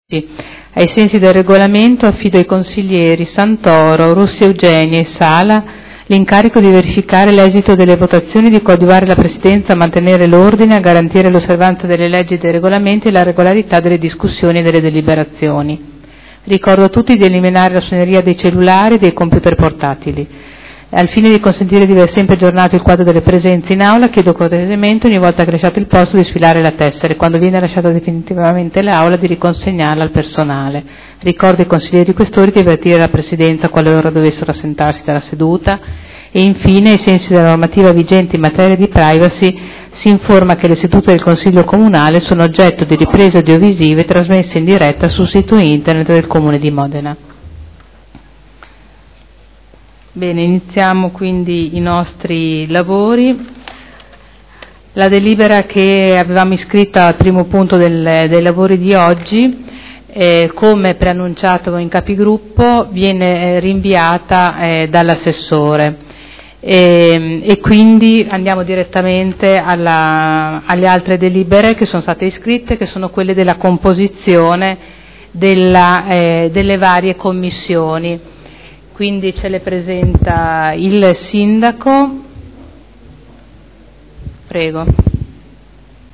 Seduta del 16/07/2012 Il Presidente Caterina Liotti apre i lavori del Consiglio